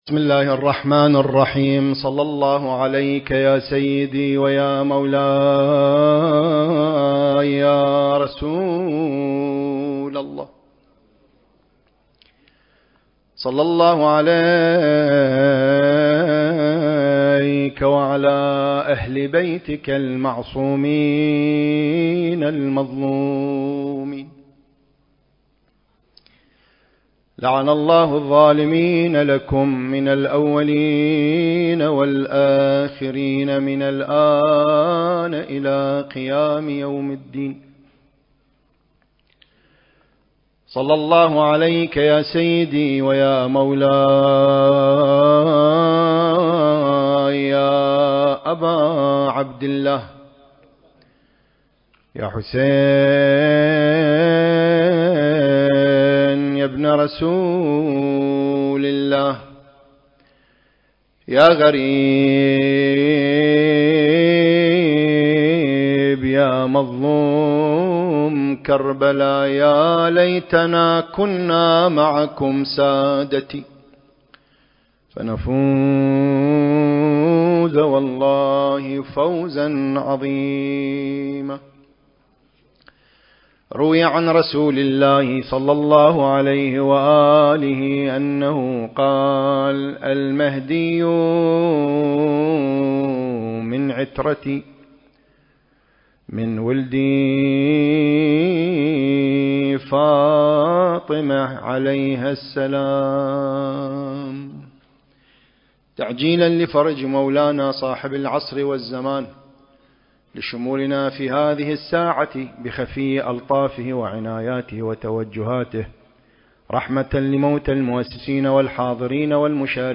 سلسلة: عقيدتنا في الإمام المهدي (عجّل الله فرجه) وأثر ذلك (1) المكان: موكب النجف الأشرف/ قم المقدسة التاريخ: 1444 للهجرة